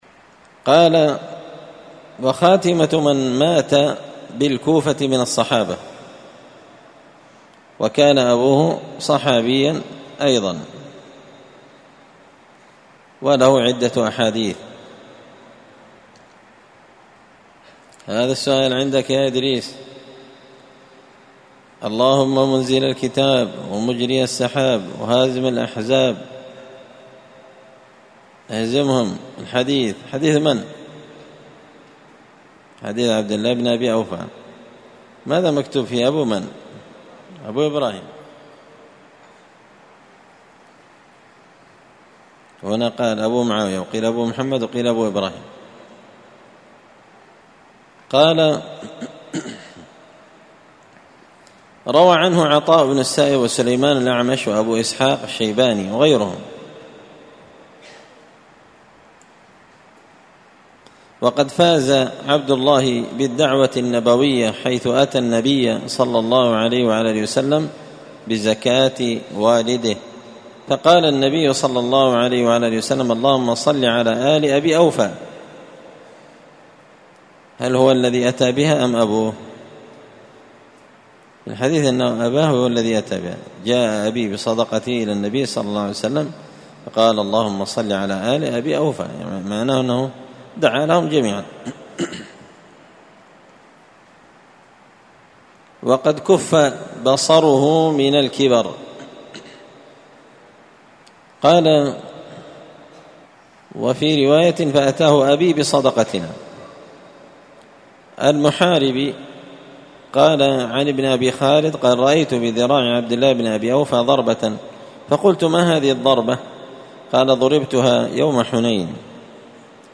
قراءة تراجم من تهذيب سير أعلام النبلاء